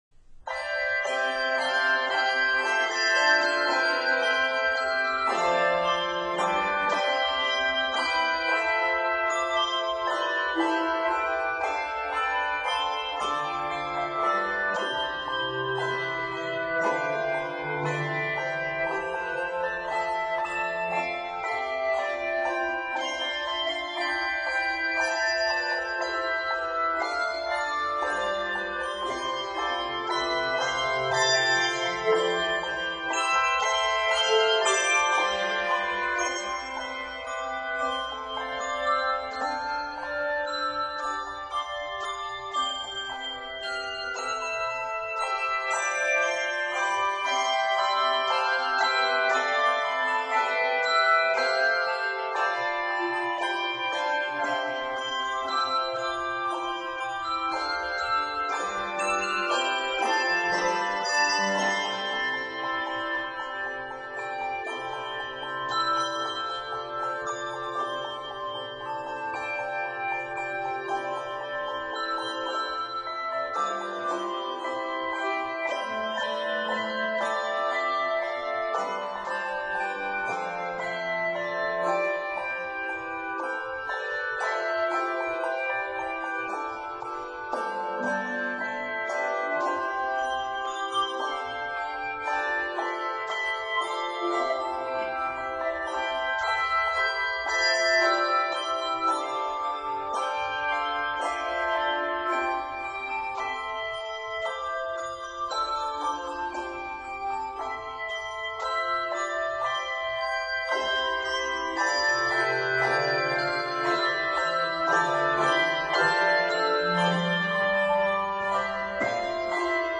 Good solid writing for bells in 127 measures.